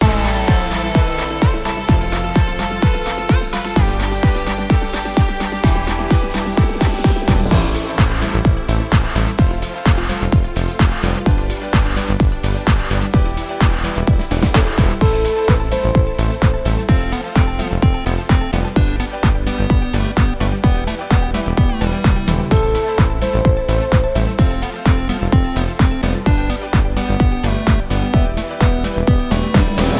Patter